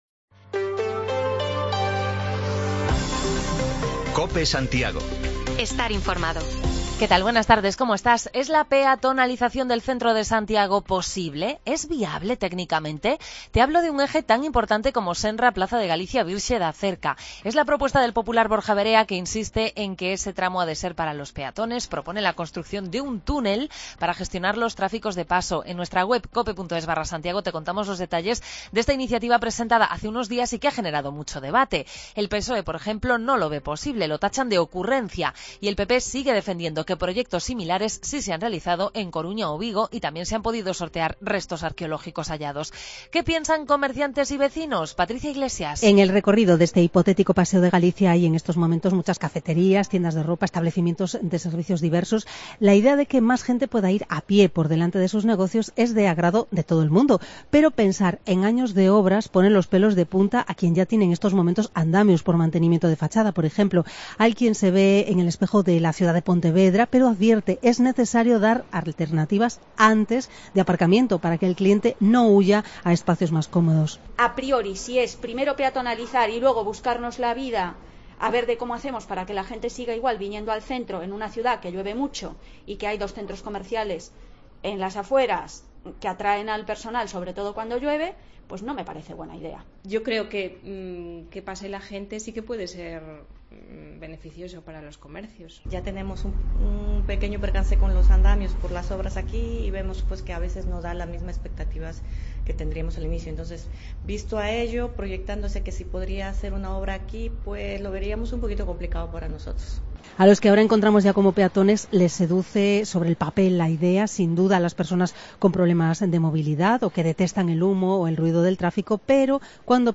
Resumen de lo más destacado del lunes: cerramos la serie de entrevistas de proximidad a los principales candidatos a la alcaldía con el candidato a la reelección, Sánchez Bugallo, y preguntamos a vecinos y comerciantes por la propuesta del Pp de hacer de preferencia peatonal desde la Senra a Porta do Camiño.